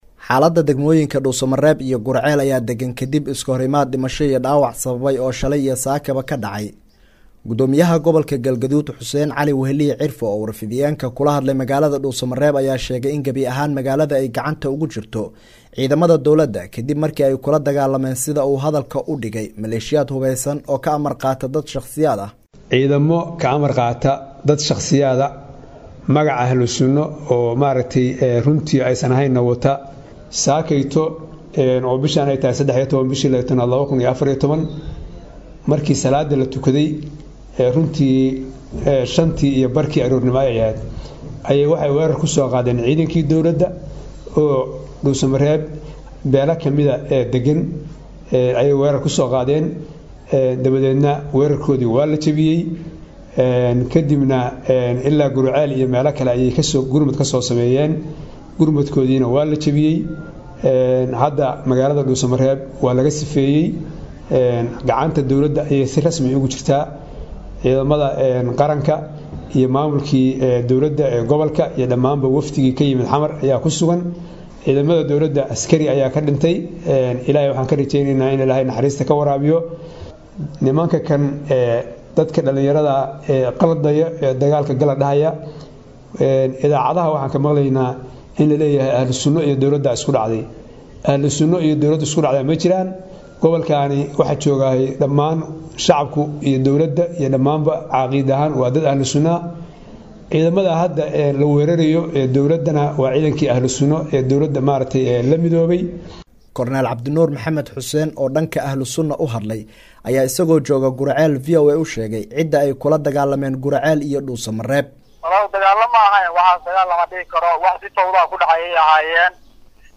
Dhegayso: Warbixin ku saabsan Dagaallo ka dhacay Galguduud